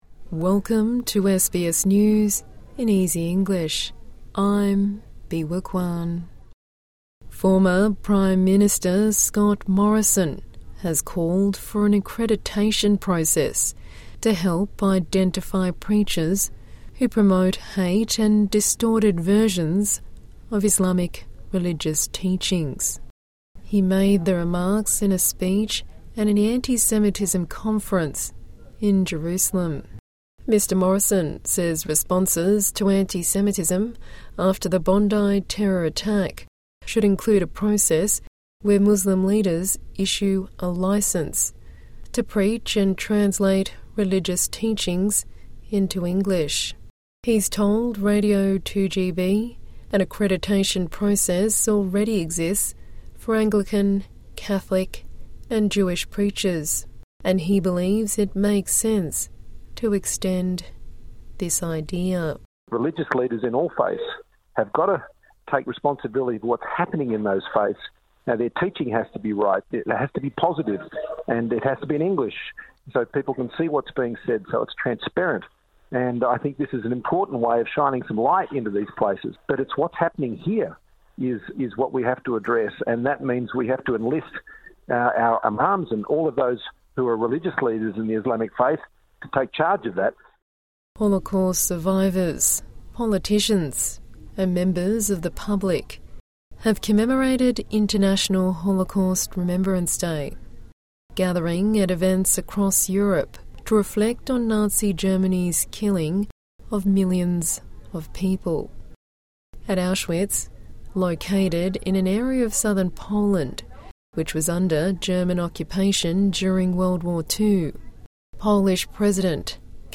A daily 5-minute news bulletin for English learners and people with a disability.